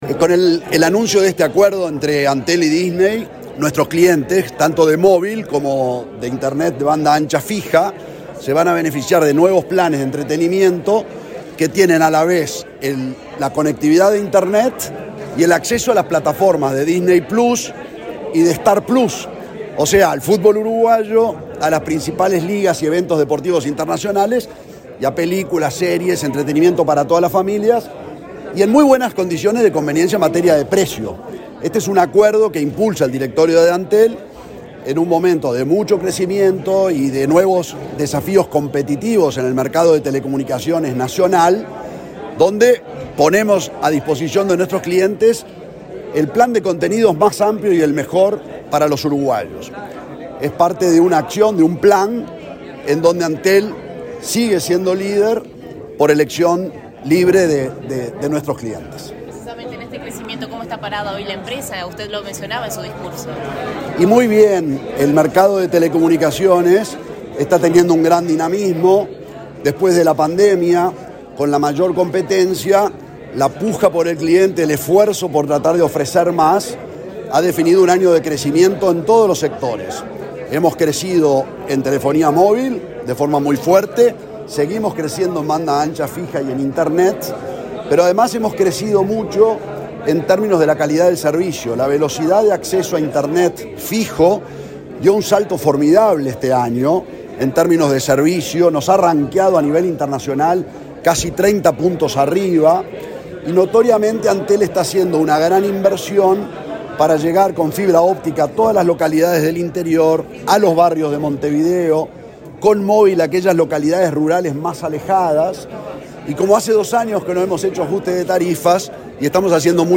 Declaraciones del presidente de Antel, Gabriel Gurméndez
Declaraciones del presidente de Antel, Gabriel Gurméndez 14/12/2022 Compartir Facebook X Copiar enlace WhatsApp LinkedIn El presidente de Antel, Gabriel Gurméndez, firmó un acuerdo con representantes de Disney Uruguay, por el cual los clientes de la empresa estatal podrán acceder a nuevos planes de entretenimiento de las plataformas de esa compañía. Luego dialogó con la prensa.